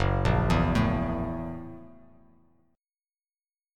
Listen to F#7#9 strummed